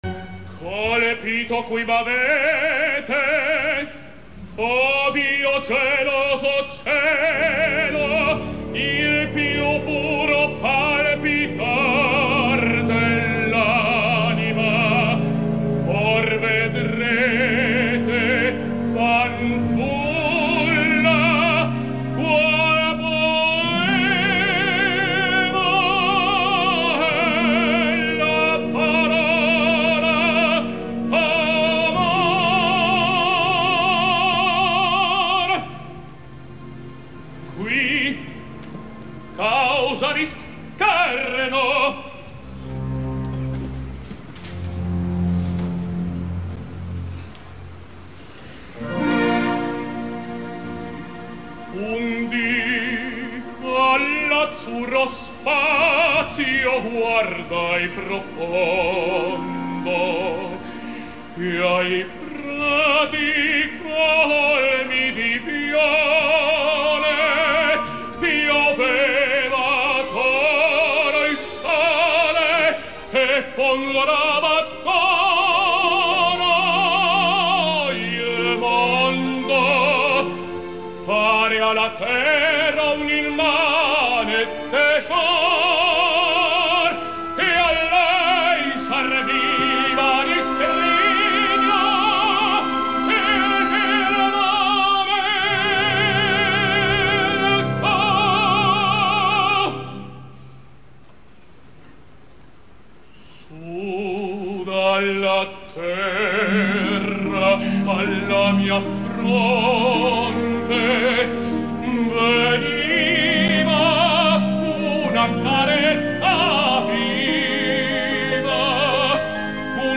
La representació és bàsicament interessant per escoltar al tenor argentí Marcelo Álvarez que si recordeu bé, va tenir alguns sonats problemes al Teatro Real que van ser motiu d’un apunt a IFL.
La veu és bonica i cada vegada s’enforteix més en el centre, si bé jo crec que ell l’emmascara una mica per semblar més spinto del que realment és.
Aquí teniu el famós “improvviso” on em sembla que Álvarez fa una notable versió. Exagera una mica les frases més altisonants i algunes vocals sonen excessivament obertes, però és evident que la veu és més idònia ara que al 2010 quan el va cantar al Teatro Real en la polèmica esmentada.
Torino, Teatro Regio, 20/1/2013